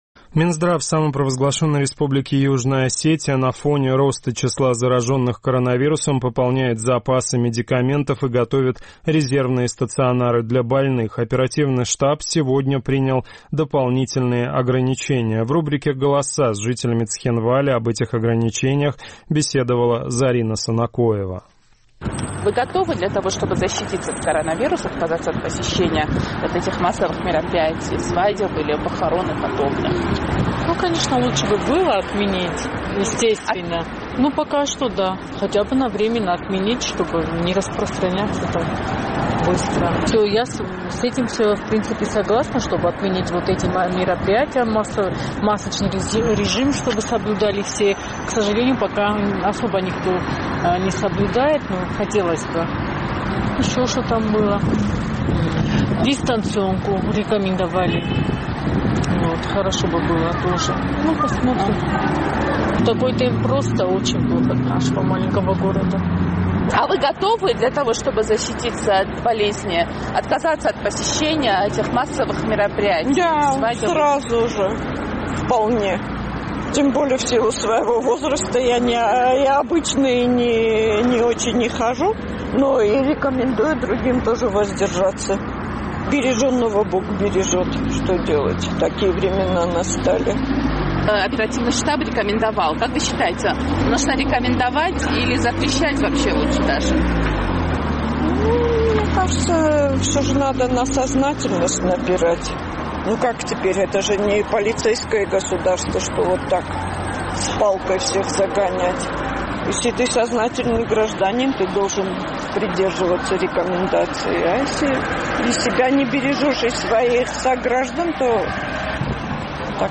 Оперативный штаб по противодействию коронавирусной инфекции рекомендовал жителям Южной Осетии воздержаться от посещения массовых праздничных и похоронных мероприятий. «Эхо Кавказа» спросило у жителей Цхинвала, готовы ли они следовать этой рекомендации.